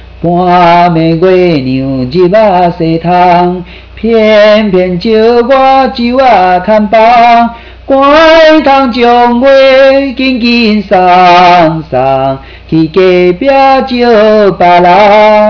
台灣國風─褒歌(一)